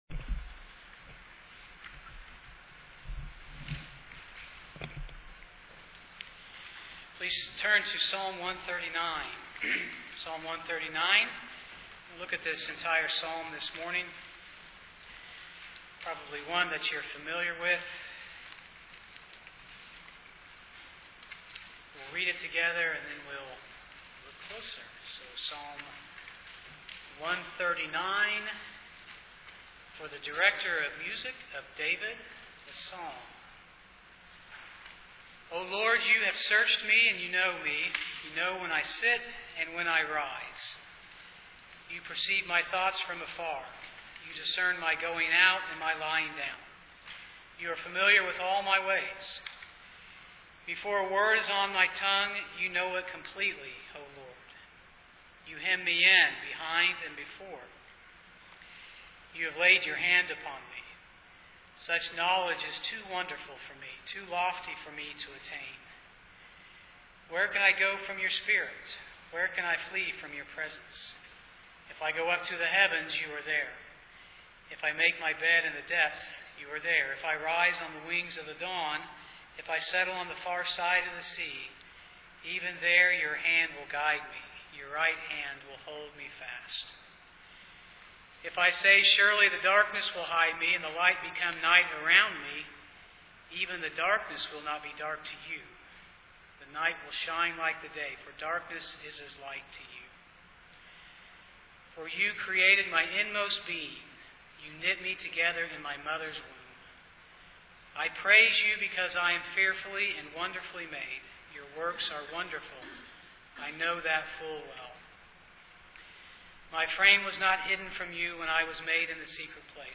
Psalm 139 Service Type: Sunday morning Bible Text